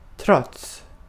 Ääntäminen
IPA : /skɔː(r)n/